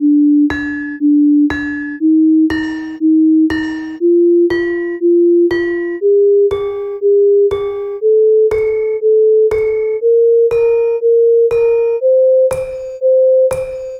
500-millisecond Saron Demung Pélog Tones of Gamelan Kyai Parijata Compared with Sine Tones of the Same Frequency.wav
gamelan, saron demung, frequencies, spectra